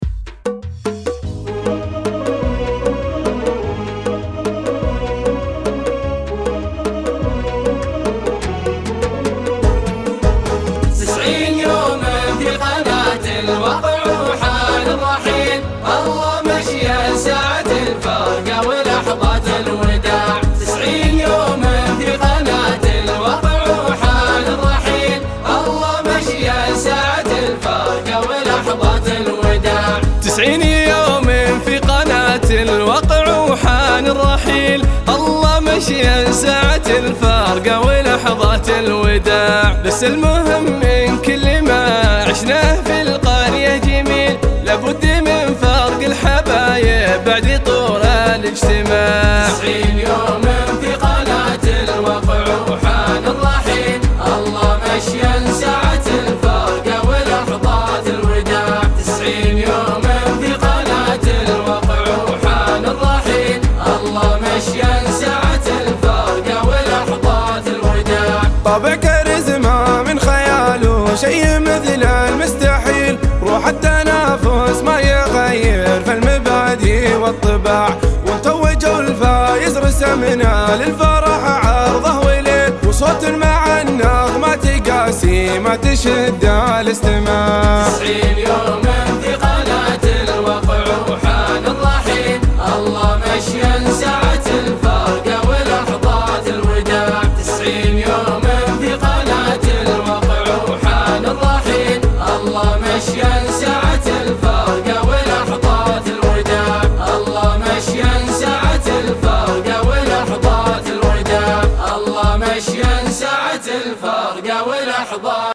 الواقع الحفل. الختامي. -اللوحه الاولى